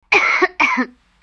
cough2.wav